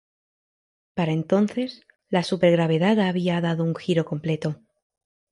Read more Adj Noun Verb completar to complete Read more Frequency B1 Hyphenated as com‧ple‧to Pronounced as (IPA) /komˈpleto/ Etymology Borrowed from Latin complētus In summary Borrowed from Latin complētus.